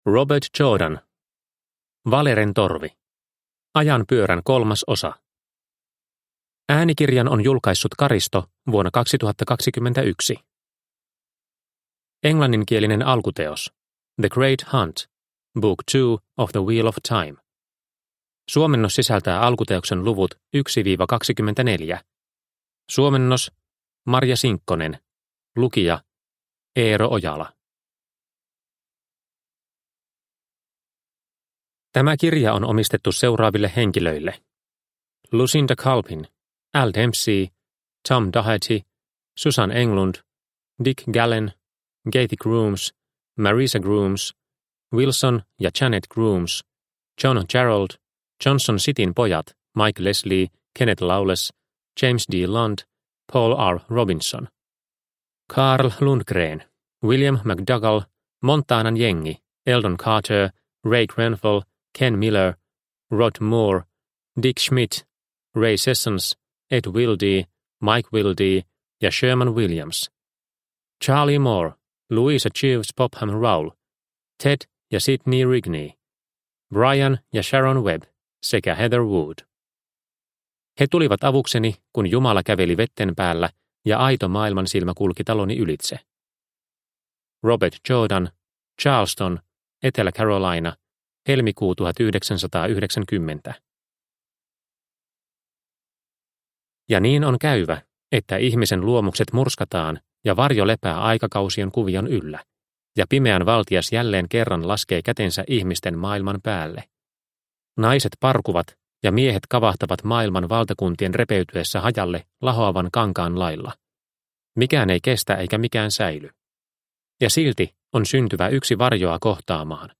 Valeren torvi – Ljudbok – Laddas ner